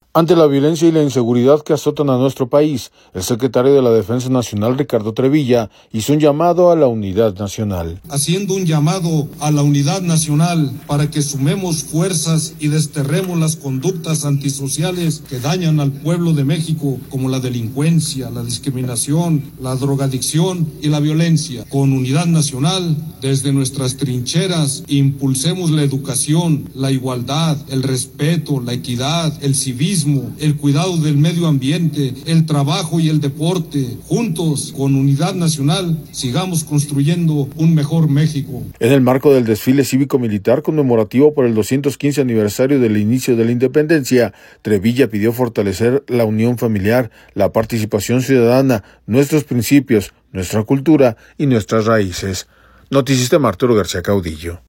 En el marco del desfile cívico-militar conmemorativo por el 215 aniversario del inicio de la Independencia, Trevilla pidió fortalecer la unión familiar, la participación ciudadana, nuestros principios, nuestra cultura y nuestras raíces.